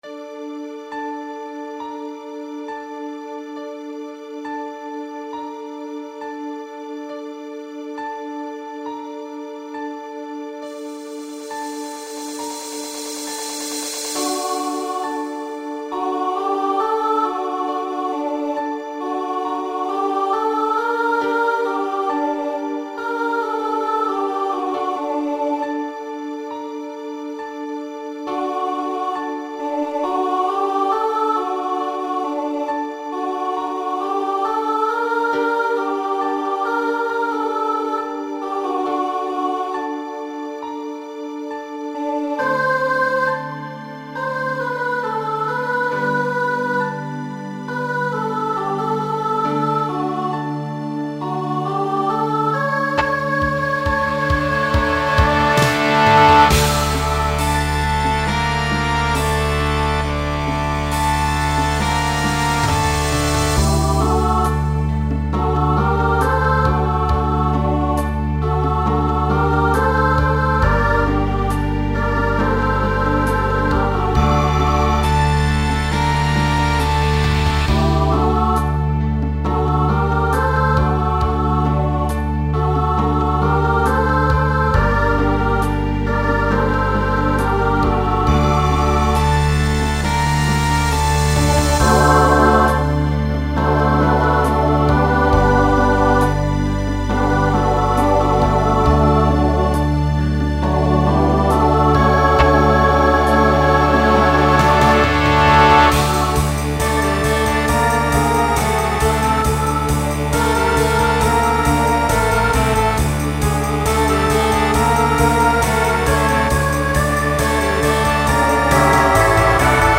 Genre Rock Instrumental combo
Function Ballad Voicing Mixed